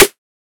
Index of /99Sounds Music Loops/Drum Oneshots/Twilight - Dance Drum Kit/Claps